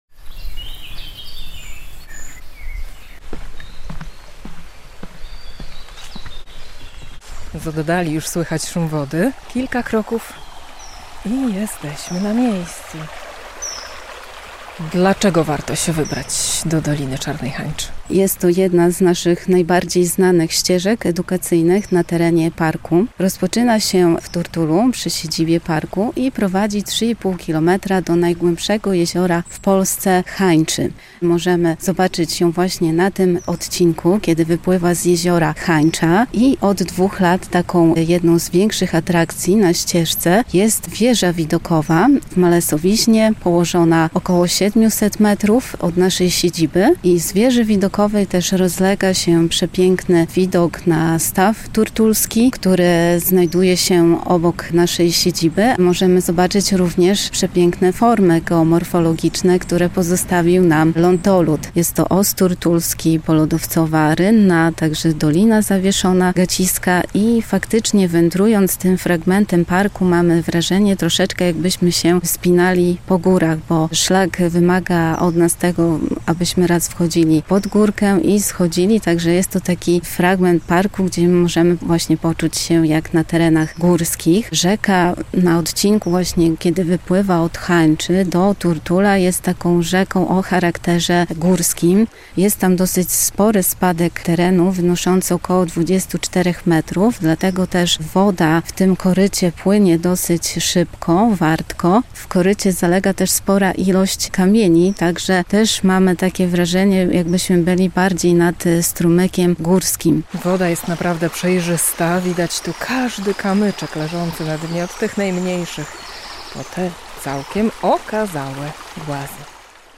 Ścieżka edukacyjna wzdłuż doliny Czarnej Hańczy - relacja
gdzie rzeka szumi niczym potok górski